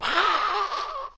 death.ogg